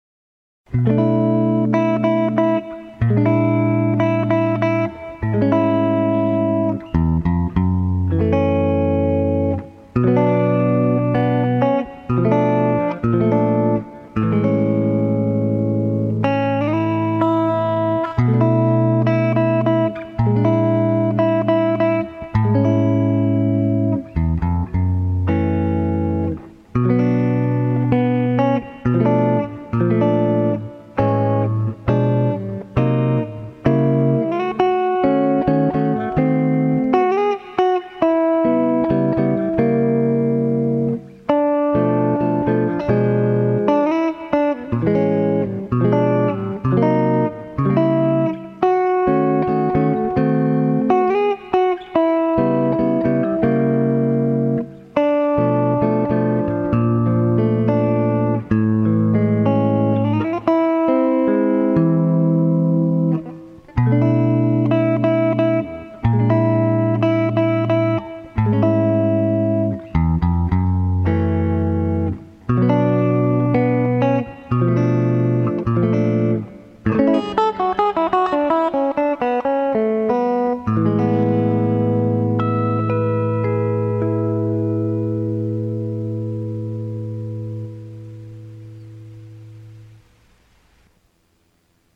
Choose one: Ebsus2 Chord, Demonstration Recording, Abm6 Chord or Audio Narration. Demonstration Recording